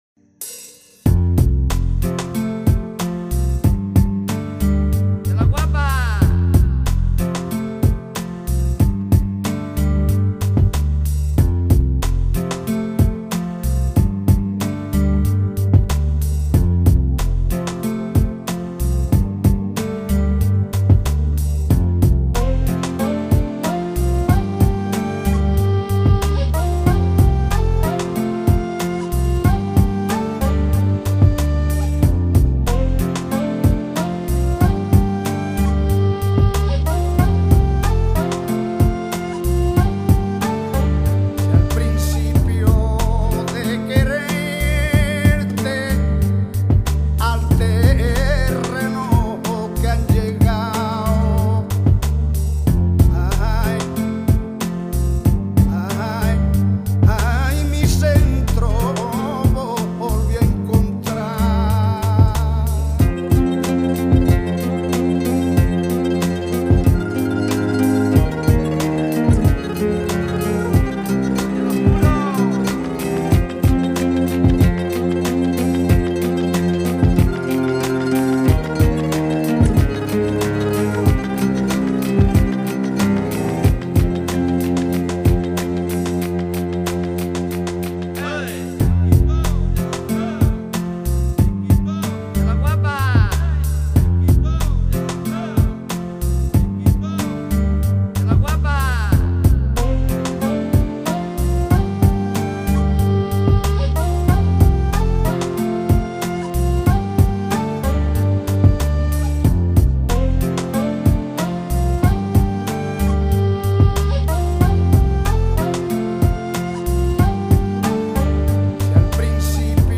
它更适合作为背景音乐或者是睡前来听。
节奏是club+ambient，风格则体现了flamenco guitar， 非洲部落吟唱和圣咏的因素。